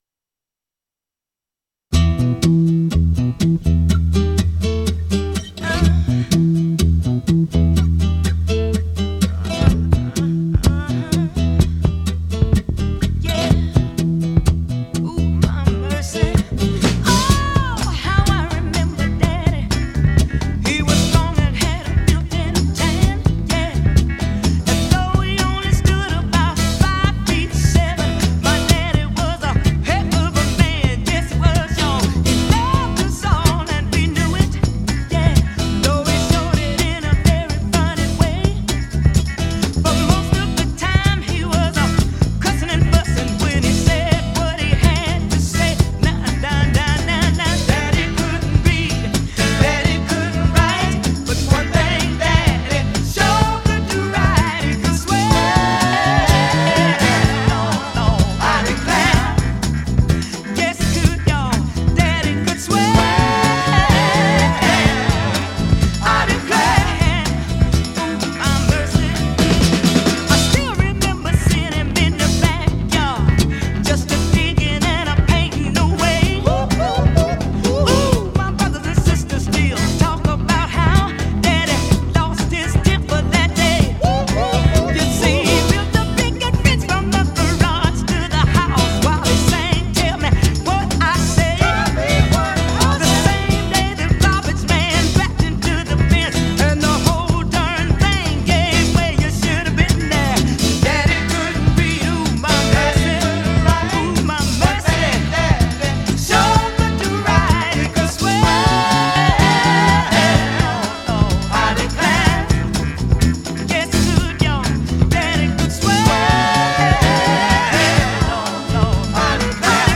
It is some soulful music.
They were a major funky band.
He was freaking great.